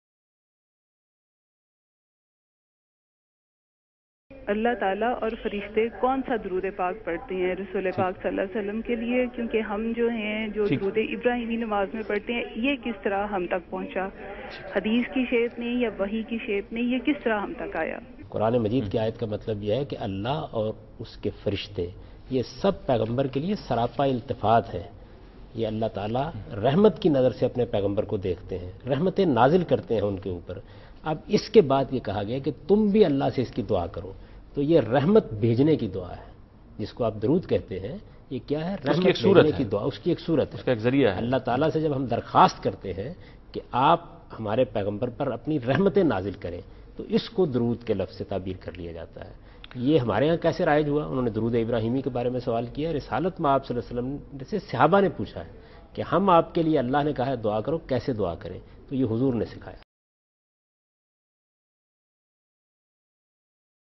Javed Ahmad Ghamidi answers a question "How do God and the Angels send Blessings on the Prophet (sws)?". This video is extracted from a TV Program Deen o Danish aired on Dunya News.